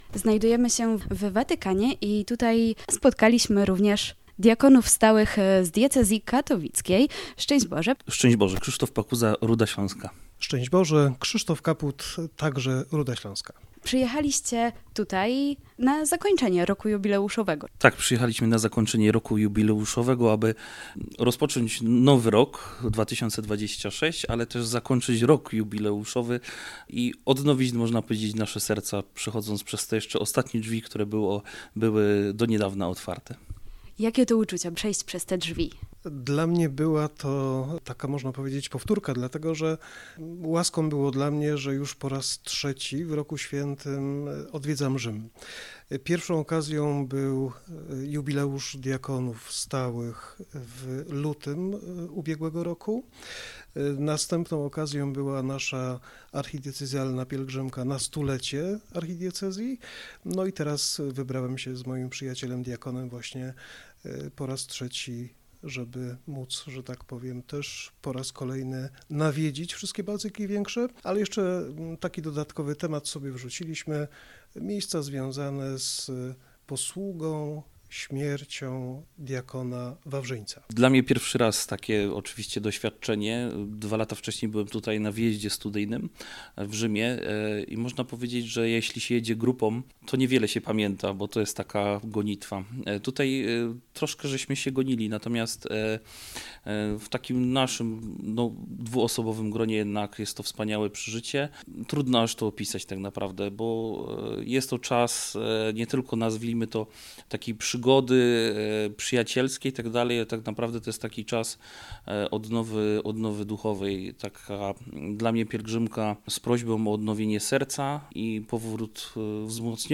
Rozmowy, które podczas naszego pobytu w Watykanie przeprowadziliśmy z uczestnikami zakończenia Roku Jubileuszowego pokazują, że był to czas głębokiego duchowego doświadczenia, odnowy serca i umocnienia wiary – zarówno osobistej, jak i wspólnotowej.